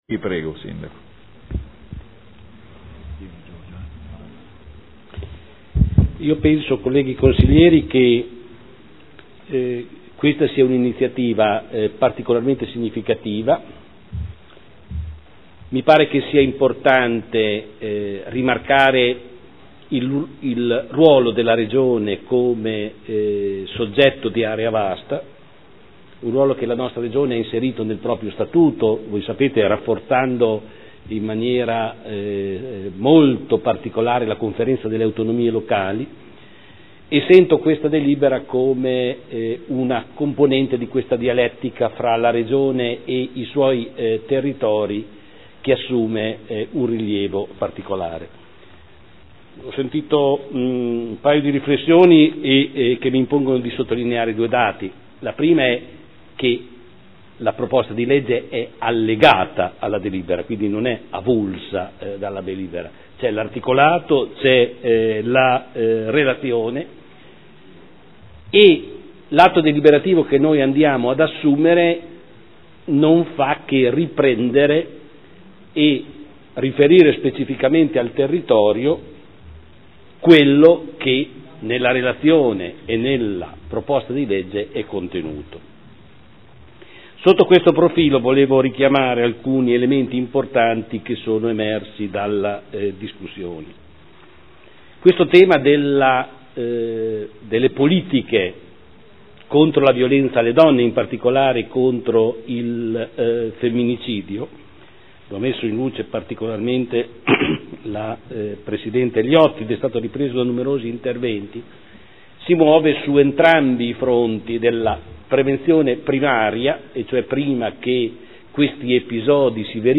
Giorgio Pighi — Sito Audio Consiglio Comunale
Dibattito.